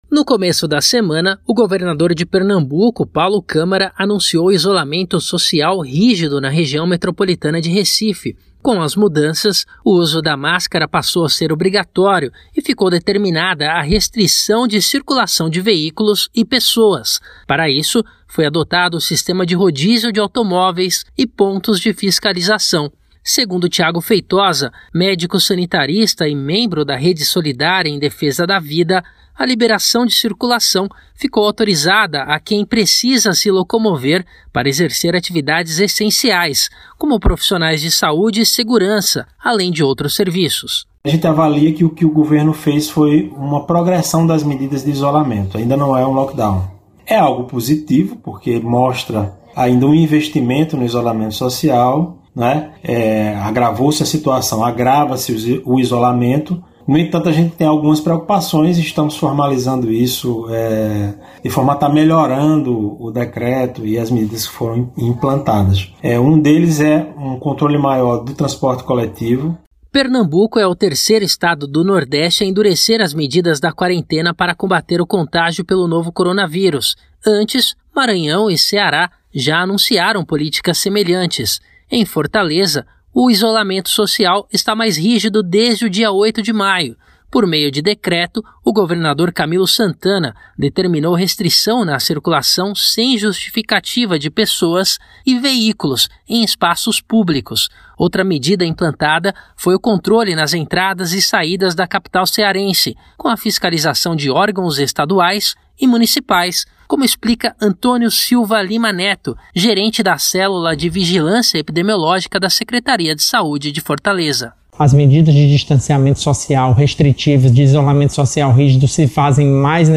videorreportagem